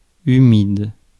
Ääntäminen
Ääntäminen France: IPA: [y.mid] Haettu sana löytyi näillä lähdekielillä: ranska Käännös Ääninäyte Adjektiivit 1. humid 2. damp US 3. moist US UK 4. wet US 5. muggy Suku: f .